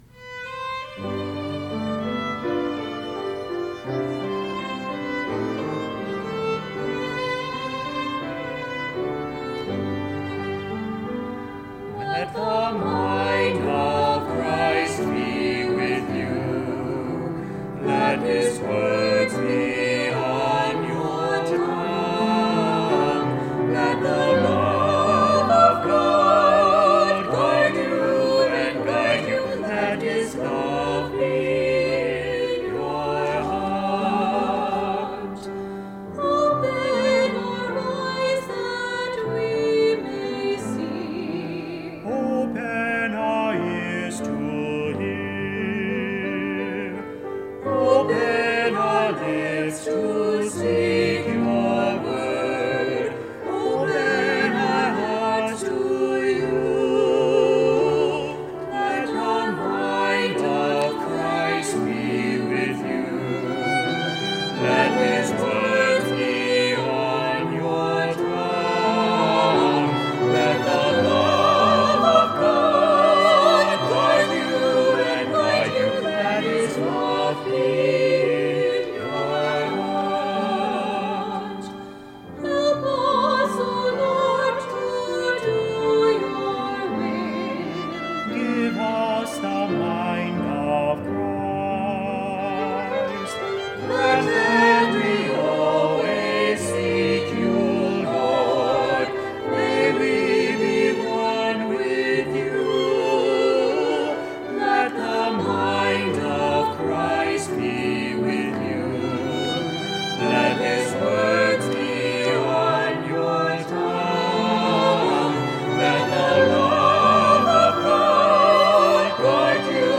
Performed by our quartet, violin, and piano.